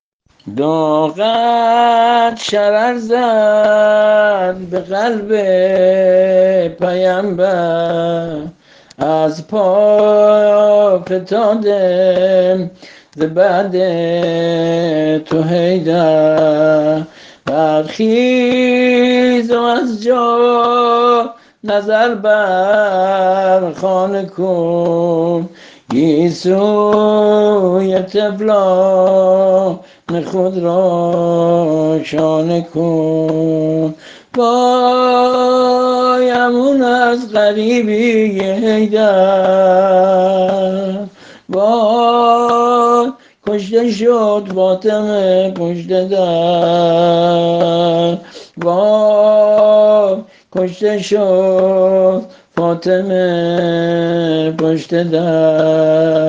◾ایام فاطمیه۱۳۹۸